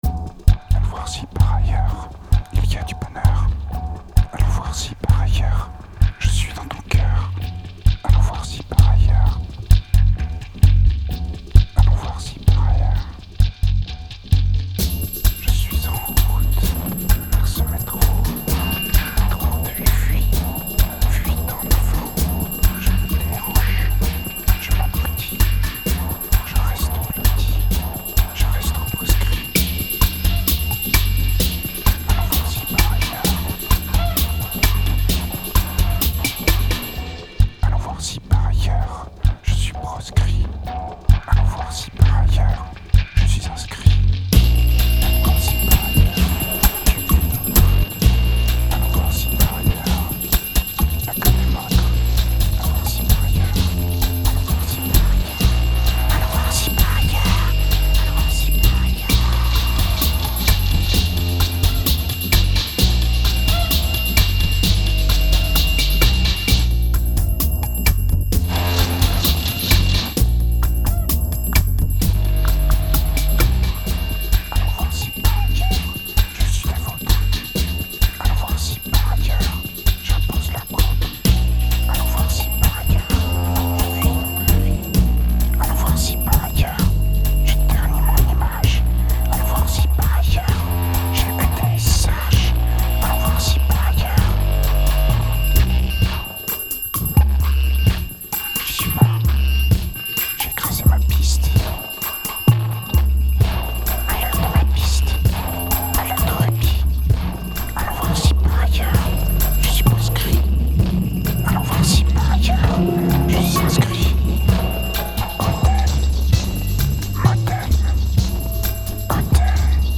Tight and progressive, it seems to flow out more naturally.
2446📈 - -22%🤔 - 130BPM🔊 - 2008-11-01📅 - -356🌟